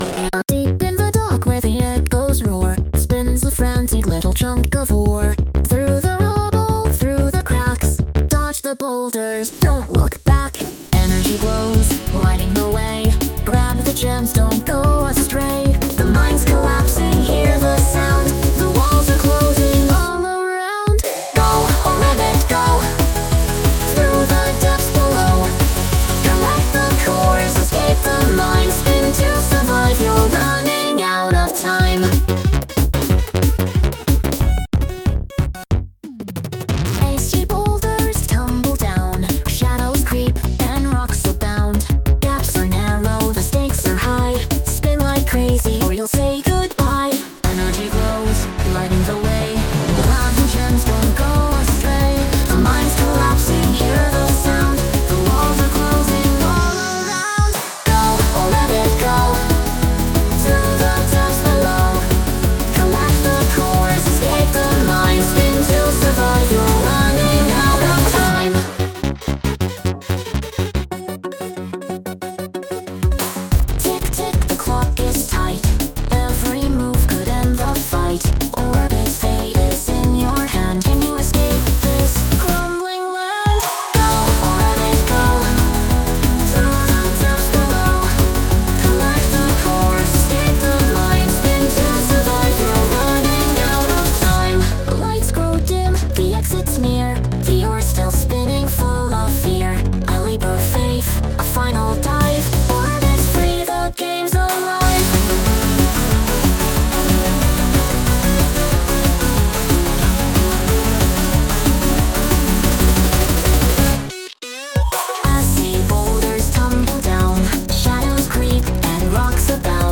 Lyrics by ChatGPT based on the game's description
I gotta say, if the game lived up to how exciting that theme tune sounds, it'd be one heck of a fun game!!!